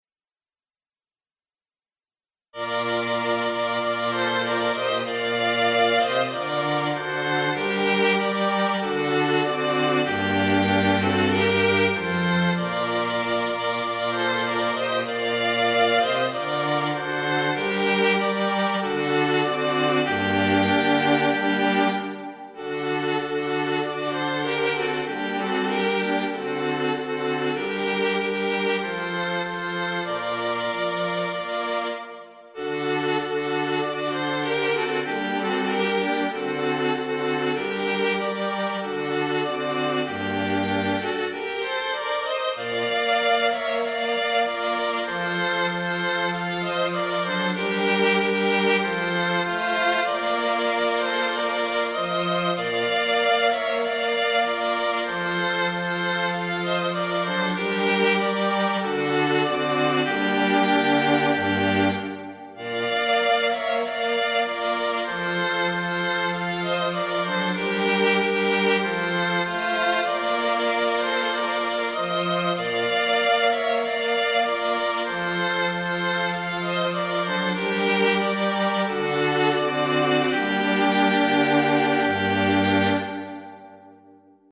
for String Quartet (2022)
an MP3 file of a MIDI demo of this composition.
A simple arrangement